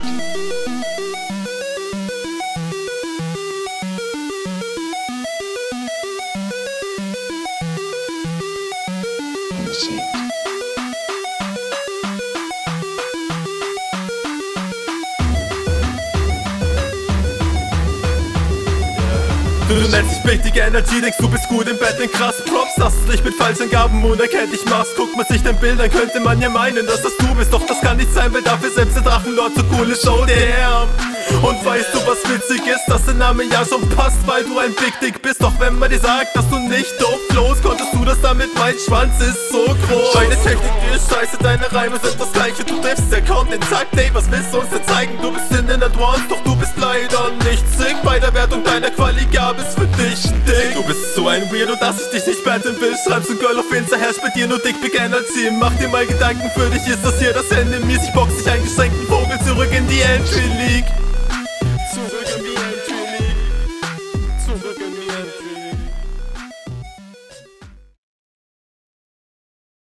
Ich feier 8 - Bit Beats aber der ist anstrengend.